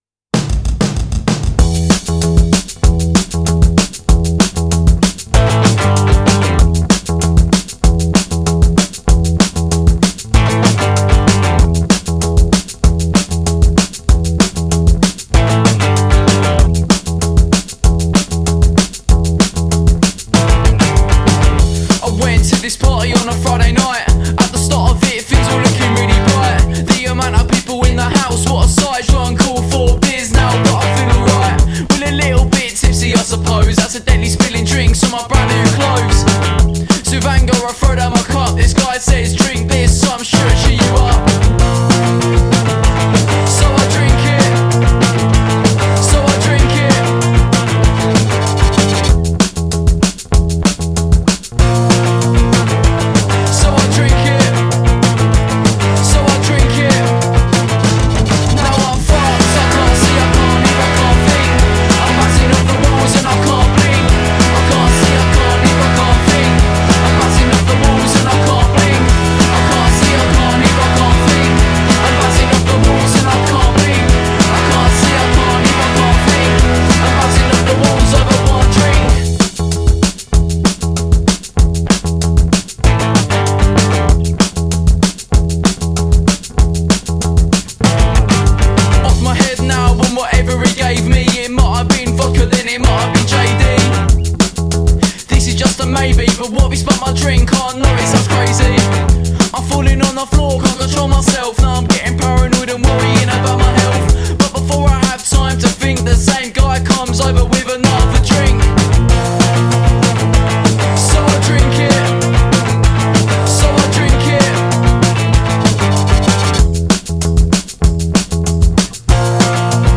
klingt urbritisch